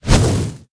fireball.ogg